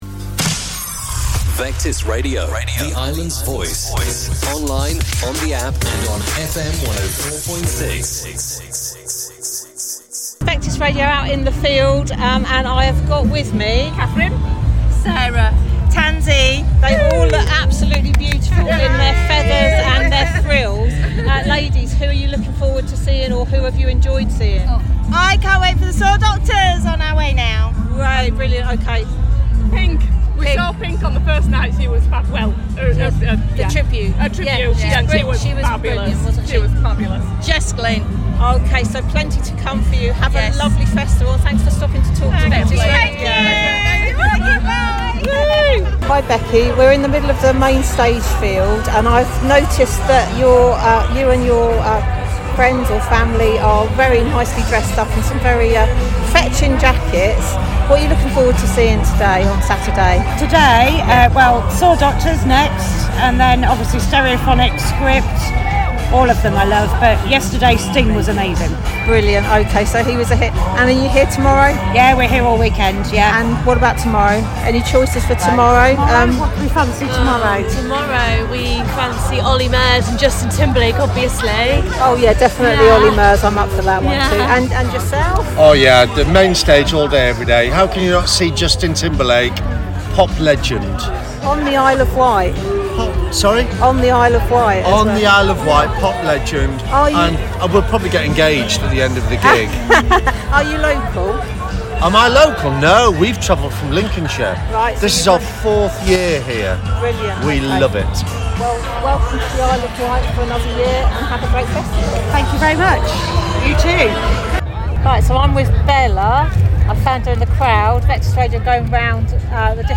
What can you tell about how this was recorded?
IW Festival 2025: Saturday Crowd Chats